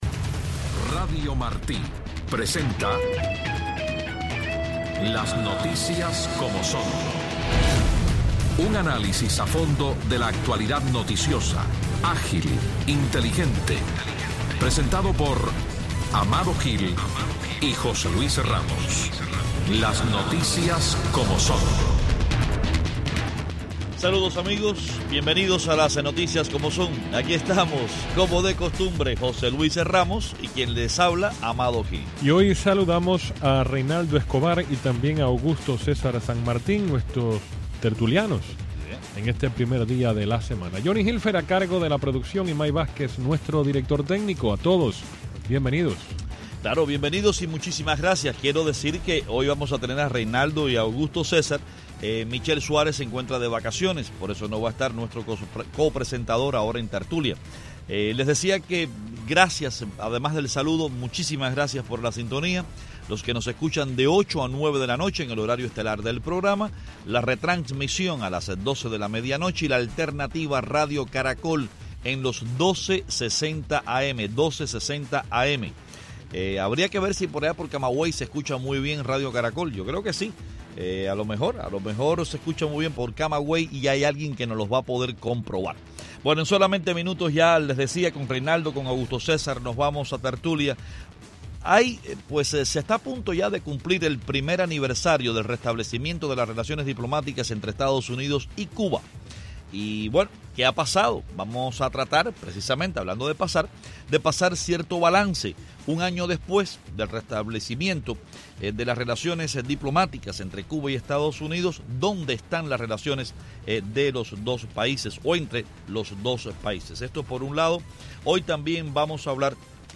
los periodistas
comentan las noticias del día, entre ellas, las autoridades cubanas quitarán las licencias de los choferes particulares que eleven las tarifas de sus viajes en La Habana.